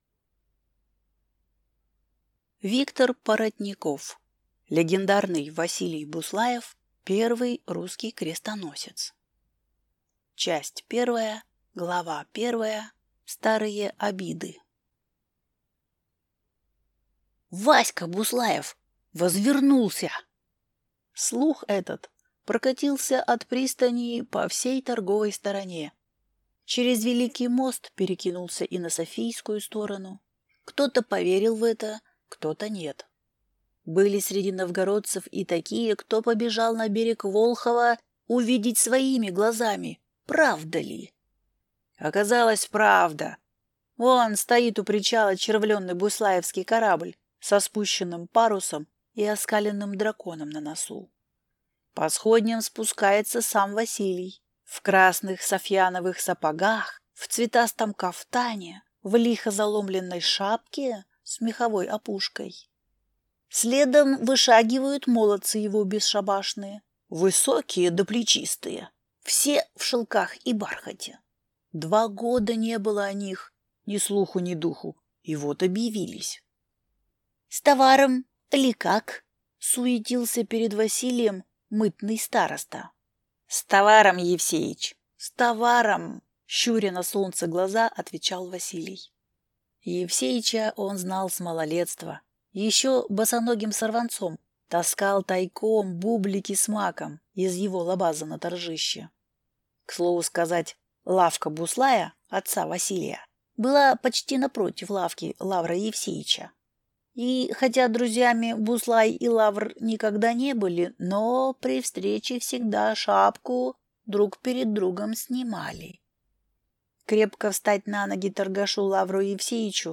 Аудиокнига Легендарный Василий Буслаев. Первый русский крестоносец | Библиотека аудиокниг